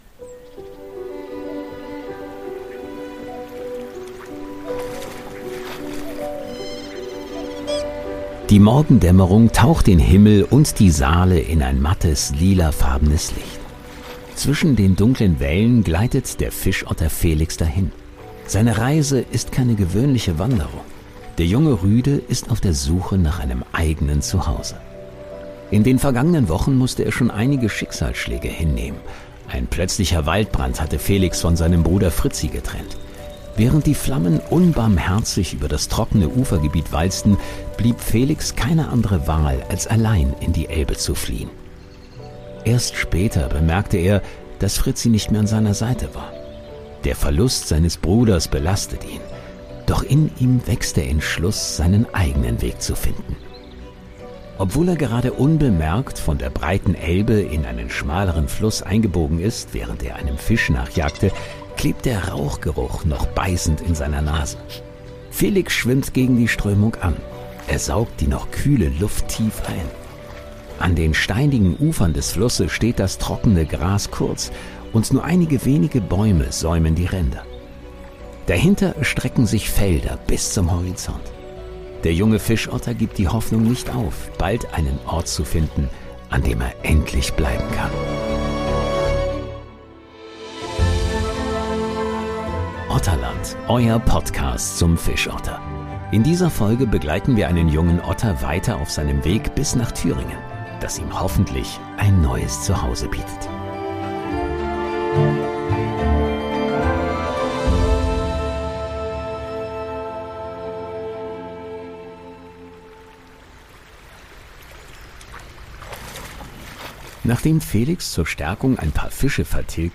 „Otterland“ ist ein Storytelling-Podcast, der eine fiktive Geschichte über eine Otterfamilie quer durch Deutschland erzählt, basierend auf realen Erfahrungen und Fakten zu Fischottern. Ein Hörabenteuer über Mut, Hoffnung, Verlust und die Suche nach einem Zuhause.